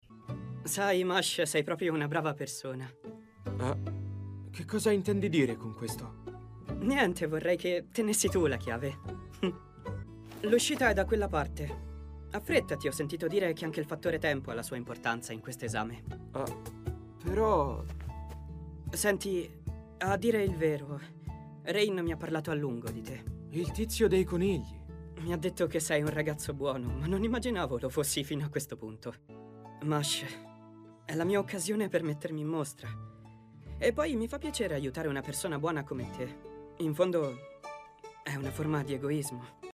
nel cartone animato "Mashle: Magic and Muscles", in cui doppia Max Land.